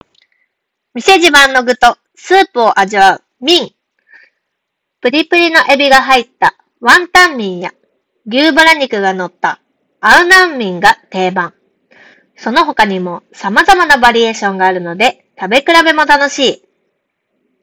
こちらのノイキャンも質が高く、周囲のノイズを取り除き、装着者の声のみをクリアに拾い上げることができていた。
聴いても分かる通り、そこそこの品質の独立型マイクで収音した場合と同程度のクオリティで、音声を拾い上げることができている。
▼EarFun Air Pro 4の内蔵マイクで拾った音声単体
声を大きくするとやや音が割れる感じもあるが、オンライン会議やゲーミング時のボイスチャット用マイクの代わりとしても十分に機能するだろう。